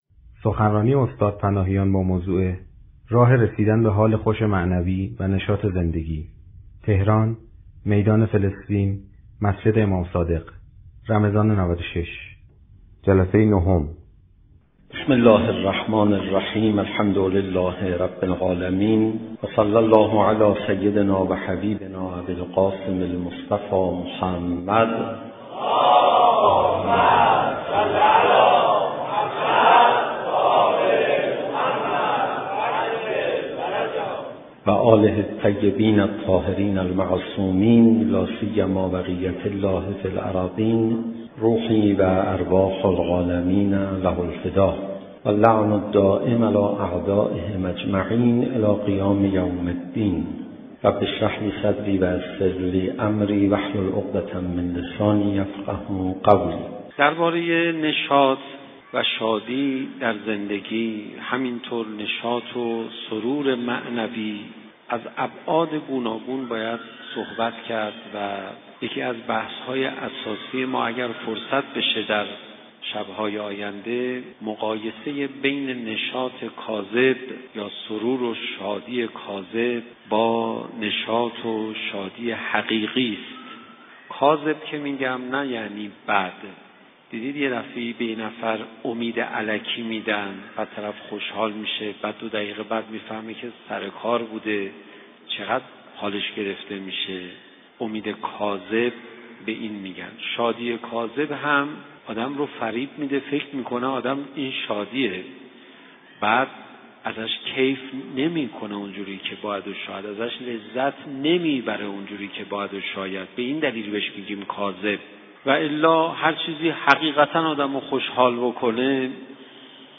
شب 9 ماه رمضان_مسجد امام صادق(علیه السلام)_راه‌های رسیدن به حال خوش معنوی